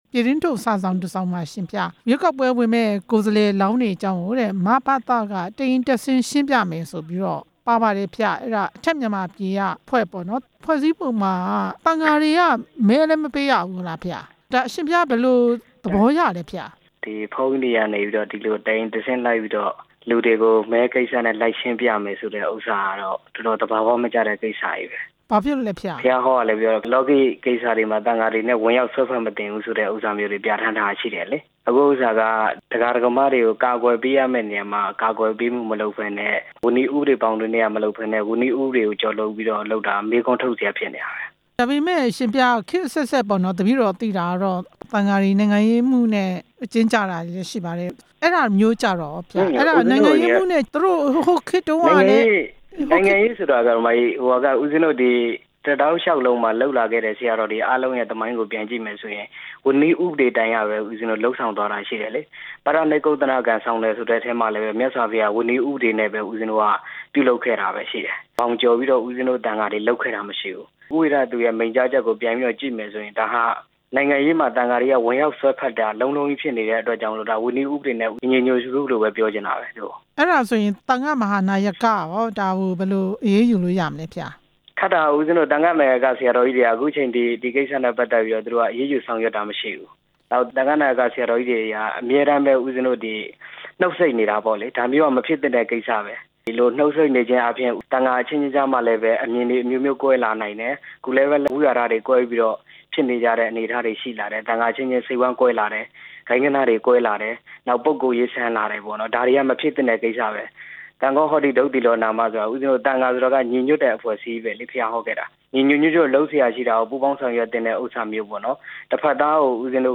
ရွေးကောက်ပွဲဝင်မယ့် ကိုယ်စားလှယ်လောင်းတွေ အကြောင်း မေးမြန်းချက်